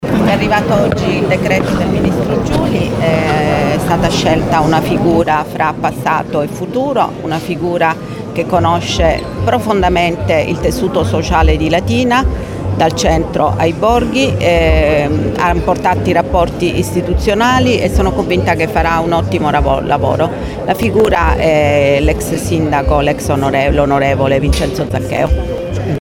Soddisfazione è stata espressa dal sindaco Matilde Celentano, che ha definito la scelta un punto di equilibrio tra memoria storica e visione futura, sottolineando la profonda conoscenza della città e delle sue potenzialità da parte di Zaccheo: